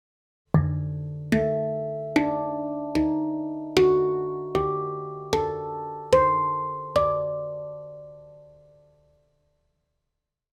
Instrumentet er laget av rustfritt stål, noe som gir en klar tone, lang sustain og balanserte overtoner.
• Stemning: D-Hijaz – eksotisk, orientalsk og emosjonell klang.
• Lang sustain med balanserte overtoner.
D3, A3, D4, D#4, F#4, G4, A4, C5, D5
Hijaz gir en eksotisk og orientalsk klang som skiller seg tydelig fra moll og dur.